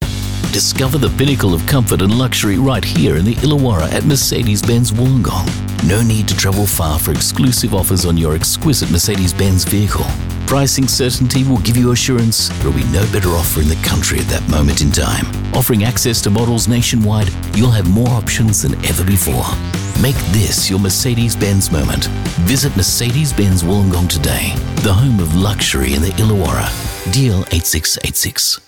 Dynamic, approachable, friendly and natural Australian VoiceOver
Radio Commercials
Mercedes -Sophisticated